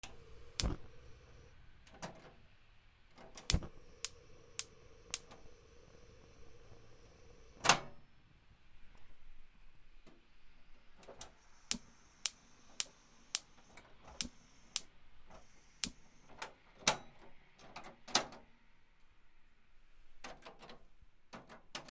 ガス 点火
『チ ボ』